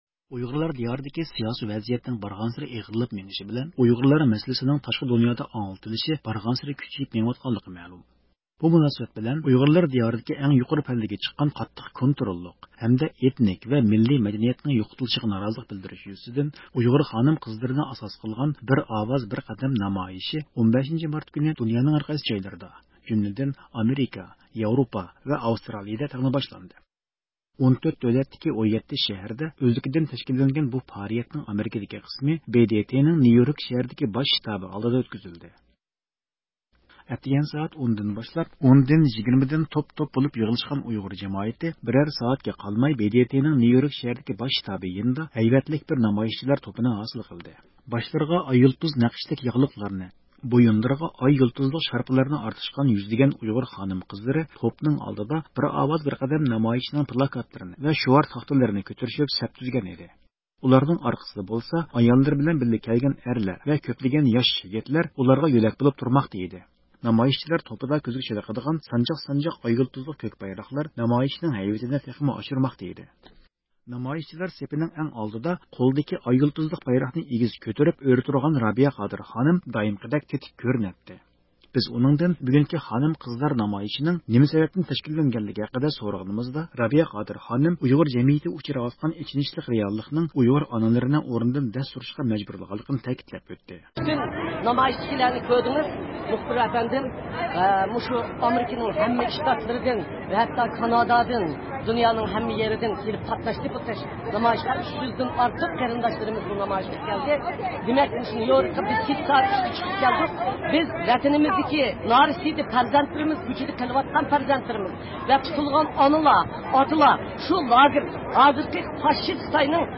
نامايىشچىلار سېپىنىڭ ئەڭ ئالدىدا قولىدىكى ئاي-يۇلتۇزلۇق بايراقنى ئېگىز كۆتۈرۈپ ئۆرە تۇرغان رابىيە قادىر خانىم دائىمقىدەك تېتىك كۆرۈنەتتى. بىز ئۇنىڭدىن بۈگۈنكى خانىم-قىزلار نامايىشىنىڭ نېمە سەۋەبتىن تەشكىللەنگەنلىكى ھەققىدە سورىغىنىمىزدا، ئۇ ئۇيغۇر جەمئىيىتى دۇچ كېلىۋاتقان ئېچىنىشلىق رېئاللىقنىڭ ئۇيغۇر ئانىلىرىنى ئورنىدىن دەس تۇرۇشقا مەجبۇرلىغانلىقىنى تەكىتلەپ ئۆتتى.